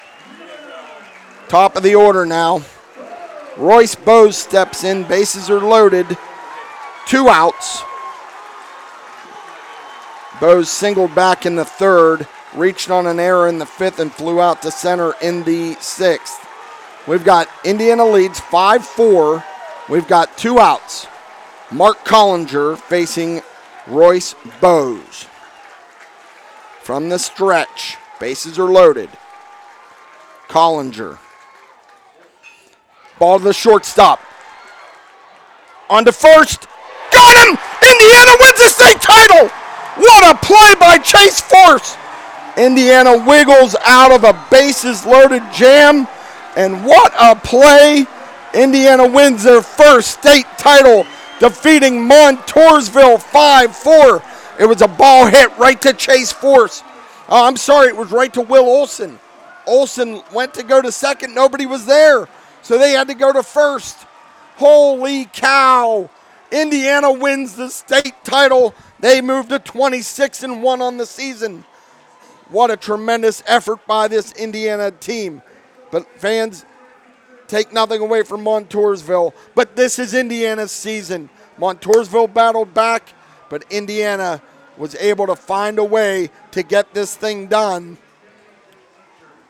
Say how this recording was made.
indiana-v-montoursville-final-call-of-the-game.mp3